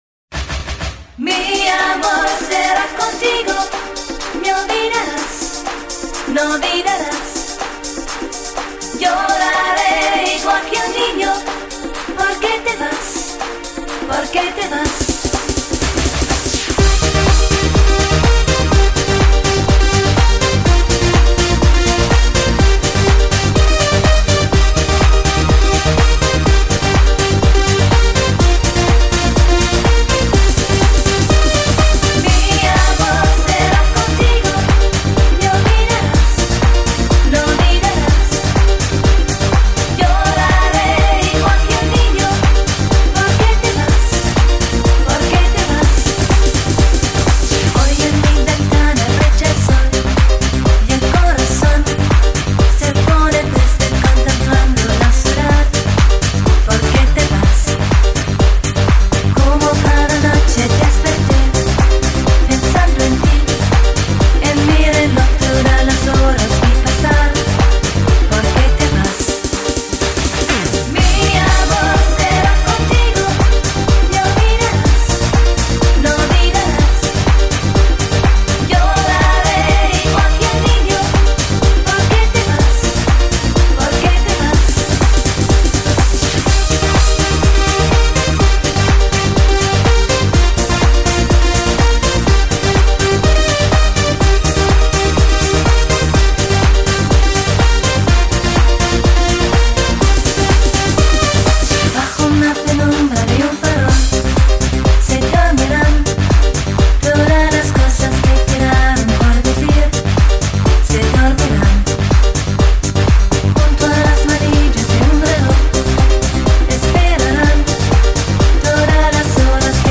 диско